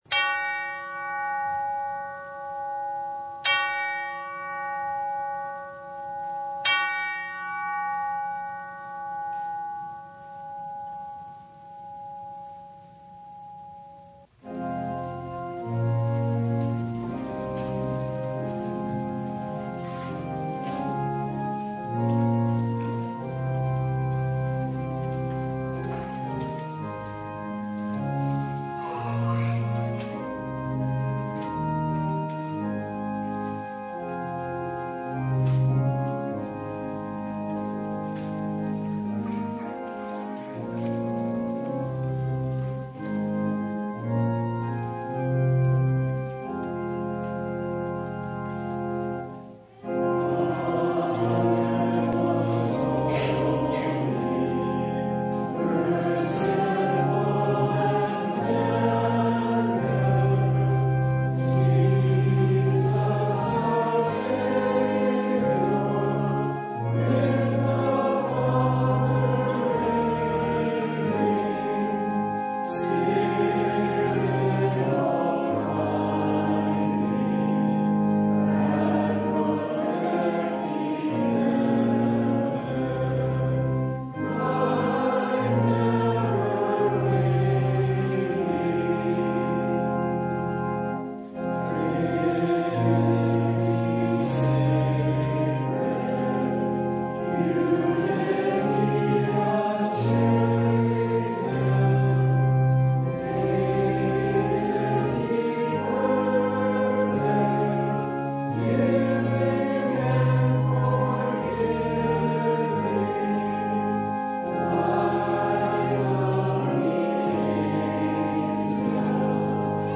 Full Service